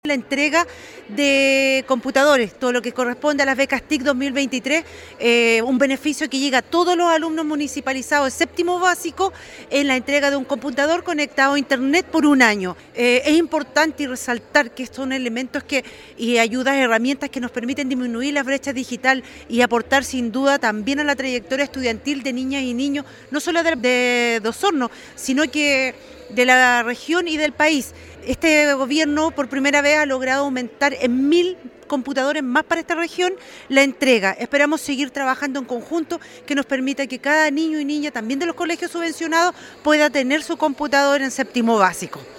En el gimnasio de la Escuela Monseñor Francisco Valdés Subercaseaux, de Osorno, y por medio de una ceremonia simbólica junto a sus alumnos y los de Escuela Juan Ricardo Sánchez, se dio el vamos a la entrega de los equipos computacionales suministrados por el “Programa de Becas de Tecnología de la Información y Comunicación” de la Junta Nacional de Auxilio Escolar y Becas (JUNAEB) y que este 2023, tiene por beneficiarios a 885 estudiantes de 7mo básico de recintos formativos municipales de la comuna.